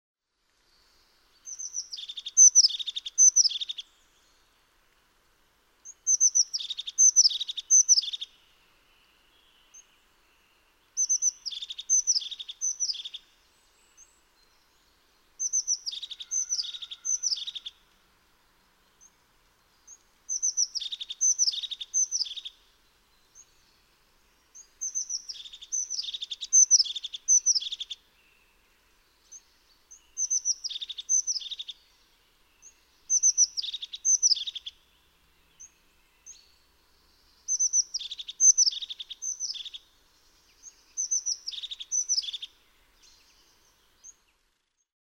Noklausies Cekulzīlītes dziesmu! cekulzilite.mp3